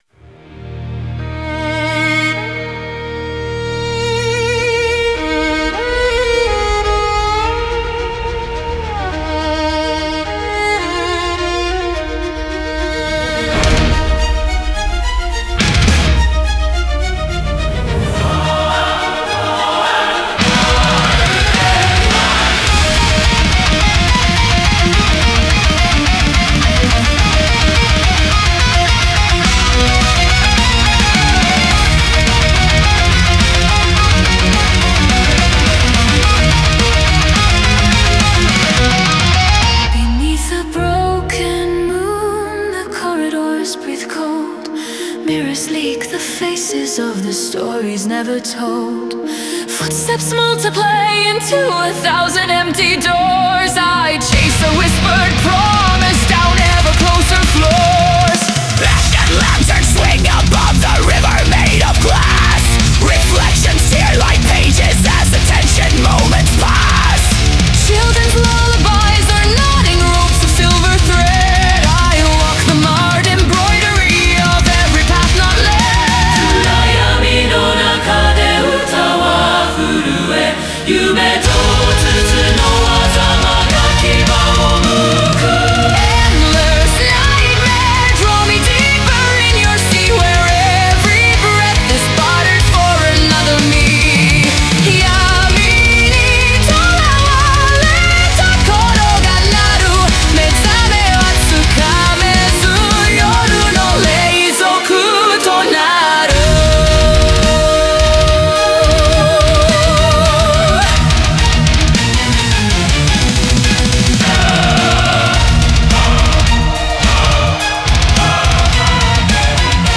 Symphonic Death Metal